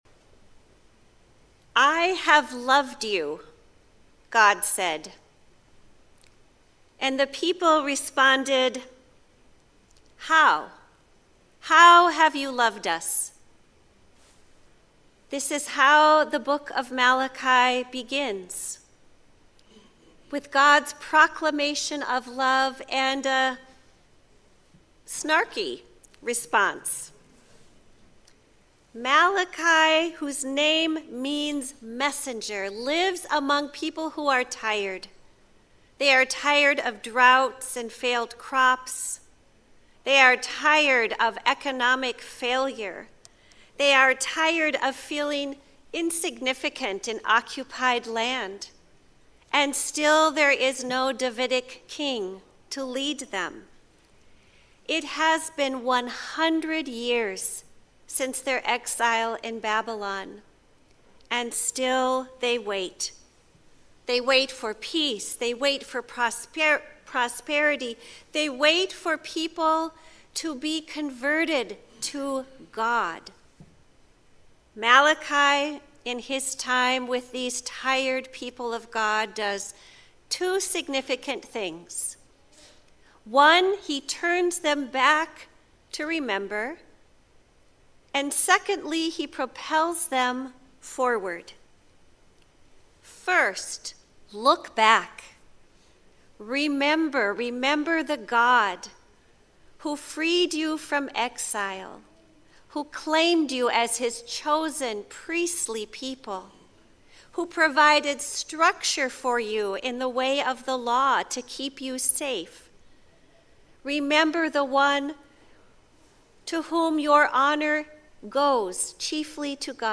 12.8.24-Sermon.mp3